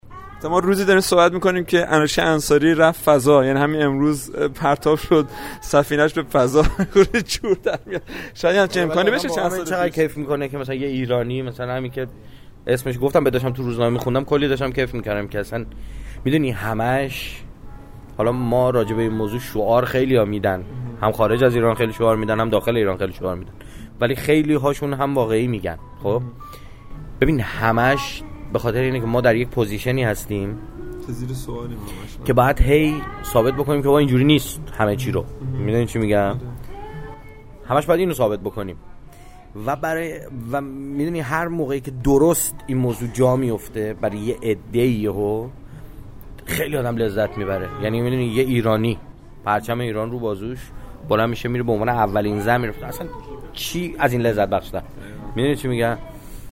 امروز عصر که دیدمش وسط مصاحبه حرف حرف انوشه شد این چند کلمه رو درباره اش گفت: